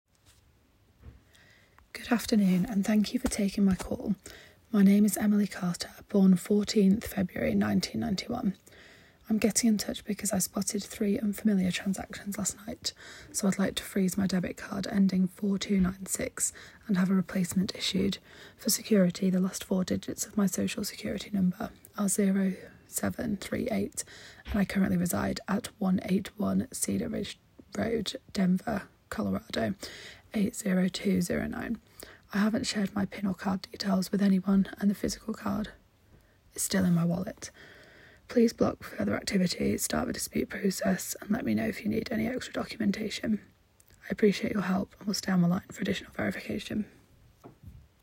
real-fake-voice-dataset-sample.m4a